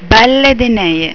(pronuncia)   farina bianca
Balle_de_neie.au